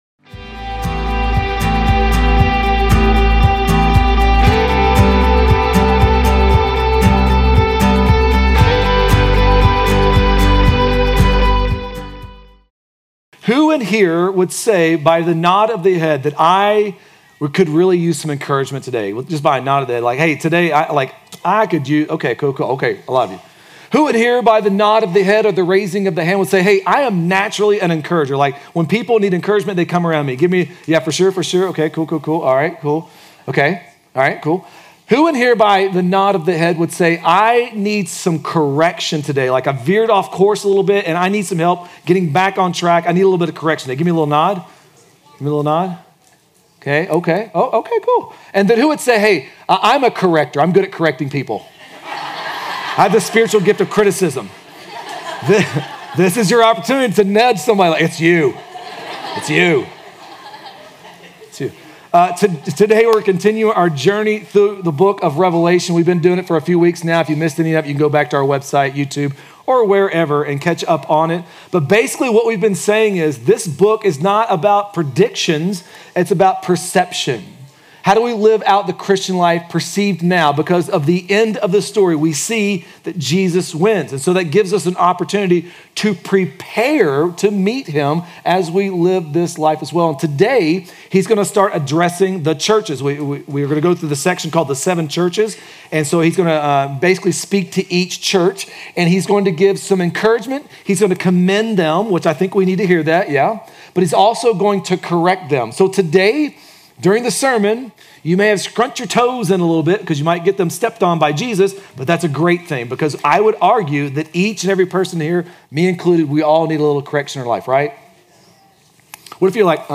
New to Grace Point Church?